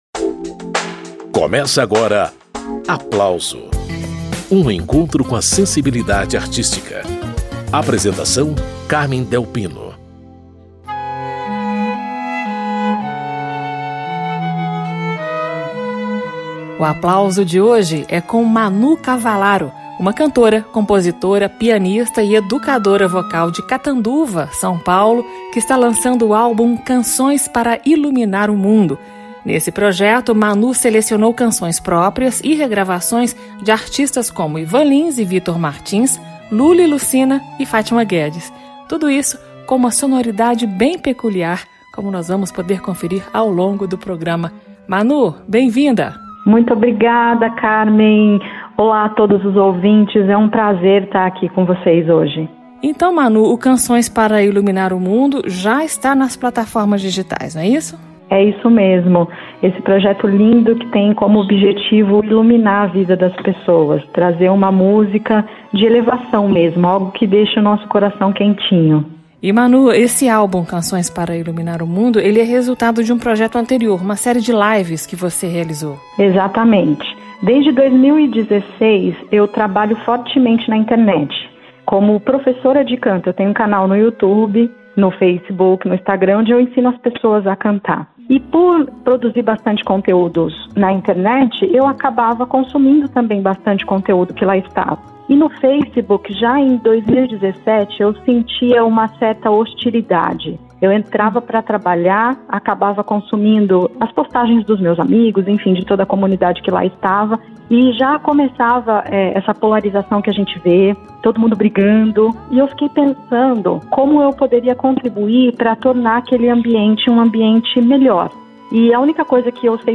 contrabaixista